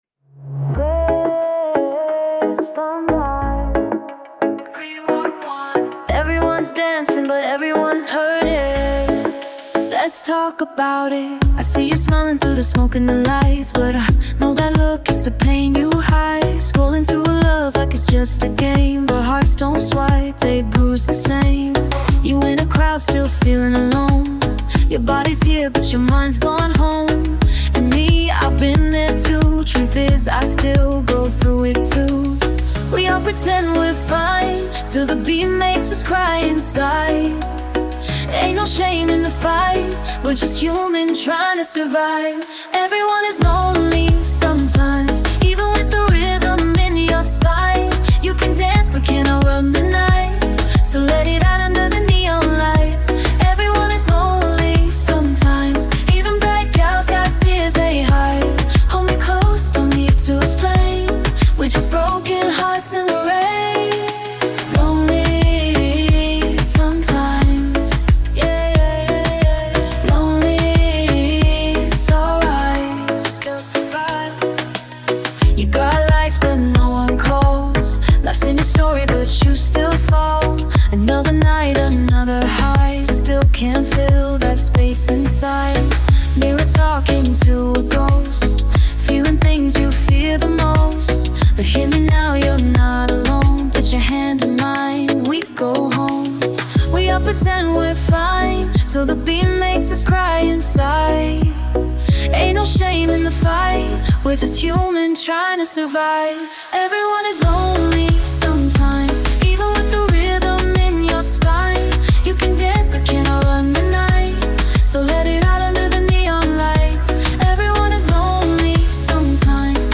Genre: Reggae, Techno-EDM, Dancehall